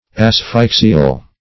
Asphyxial \As*phyx"i*al\